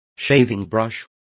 Also find out how brocha is pronounced correctly.